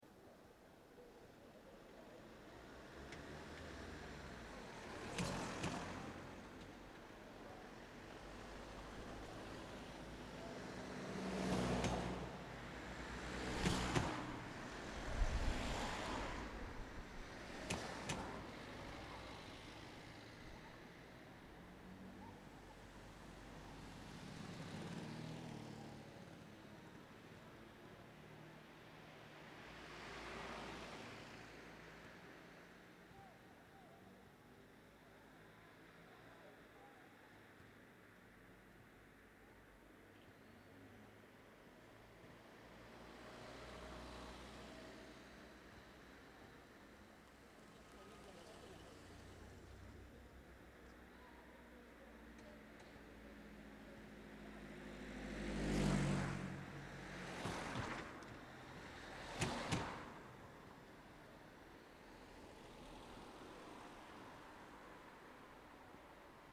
Título Tráfico denso IES Albaida CFGM VDJ y Sonido Formato: audio/mpeg Tamaño de archivo: 1.02 MB Duración: 66 seconds: Me gusta Descripción Grabación de una calle con bastante tráfico.